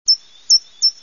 ogrodowy.wav